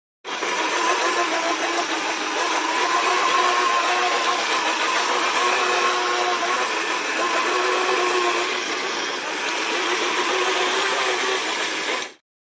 fo_toy_motor_09_hpx
Small toy motor spins at variable speeds. Motor, Toy Buzz, Motor